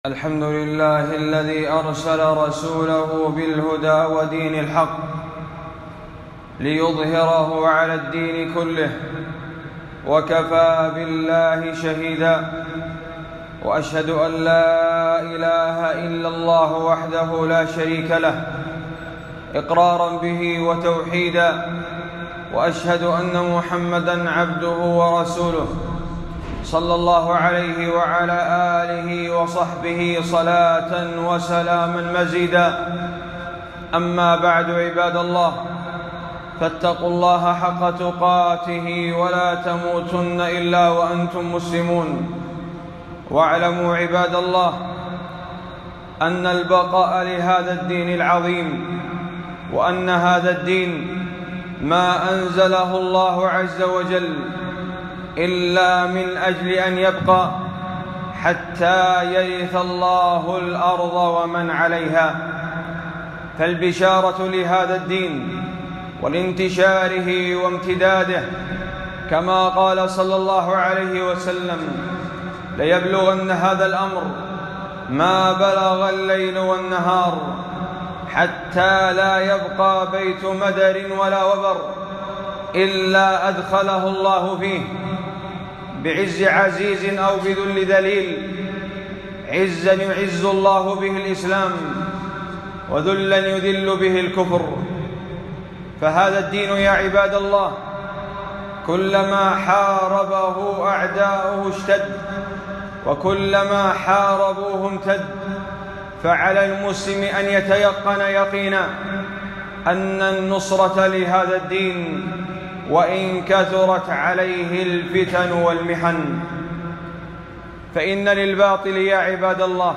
خطبة - البقاء لهذا الدين والحذر من ظن المنافقين